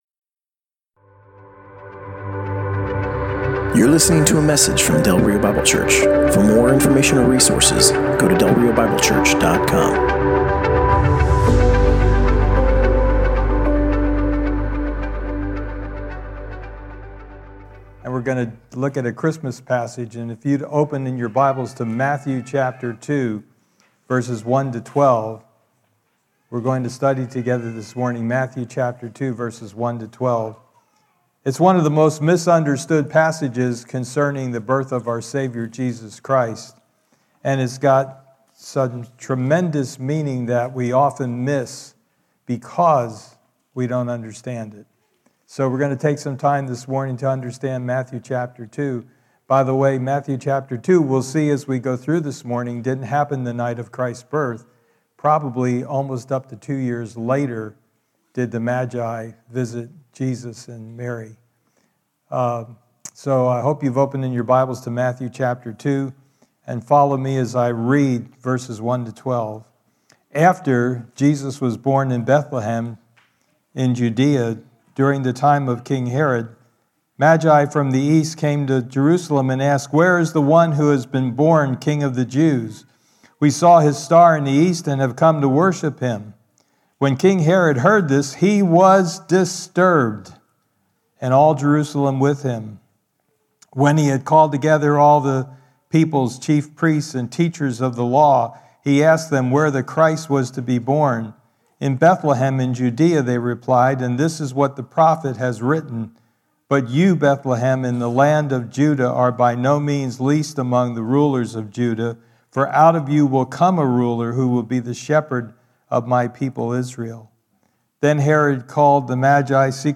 Passage: Matthew 2: 1-12 Service Type: Sunday Morning